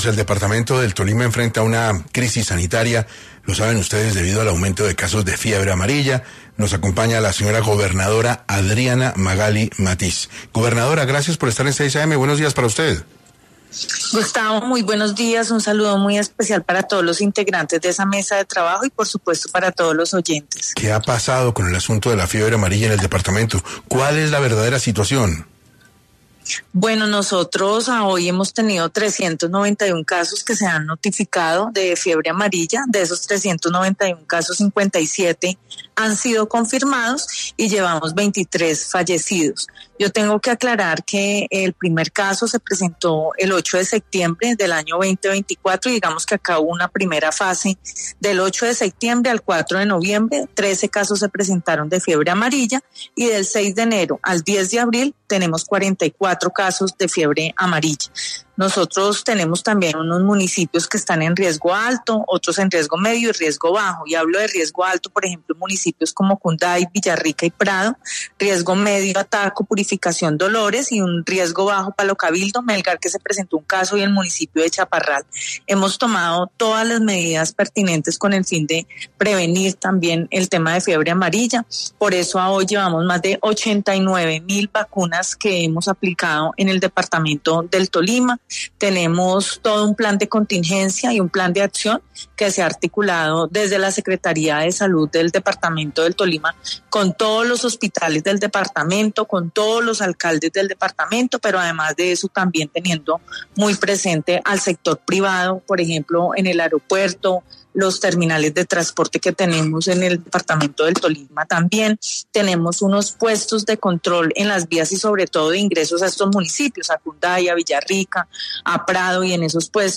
Adriana Magali Matiz, gobernadora del Tolima, estuvo en 6AM para hablar de la crisis sanitaria derivada del problema de la Fiebre Amarilla.